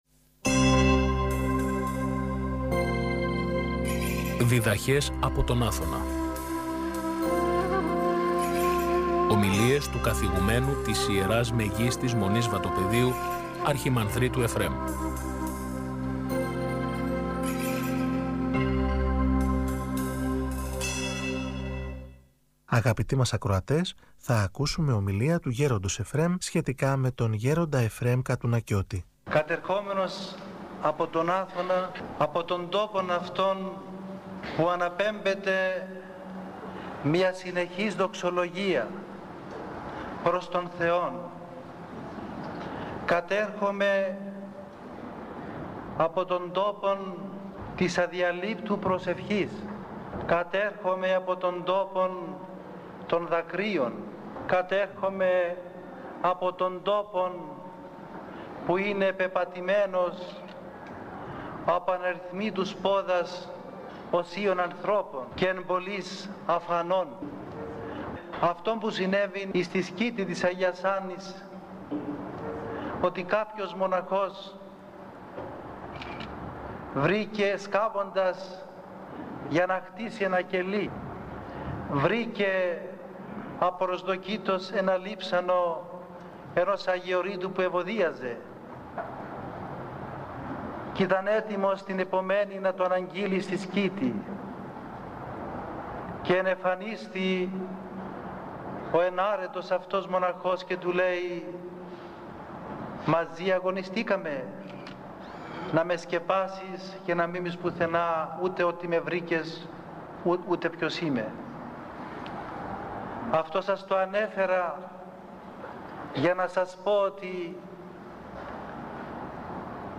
Ομιλία